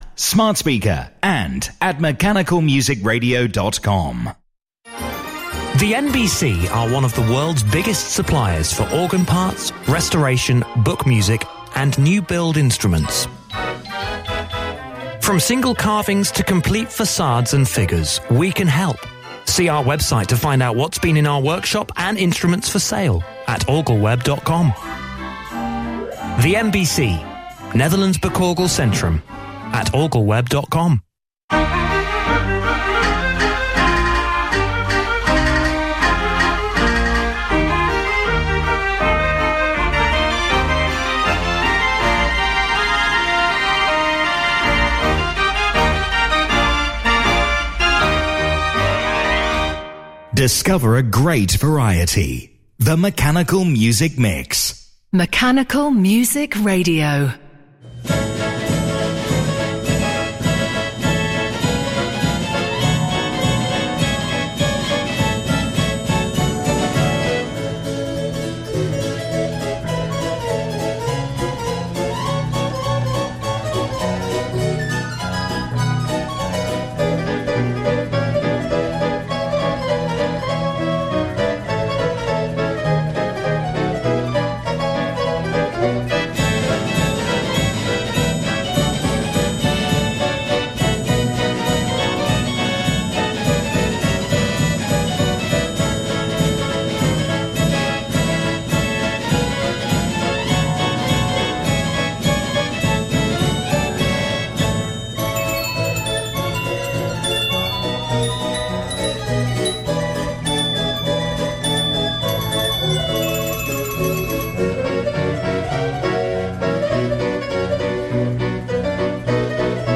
You're never far away from a style of mechanical music you love. There'll be plenty of surprises and music you don't hear elsewhere!– Lyt til Mechanical Music Mix af Mechanical Music Mix øjeblikkeligt på din tablet, telefon eller browser - download ikke nødvendigt.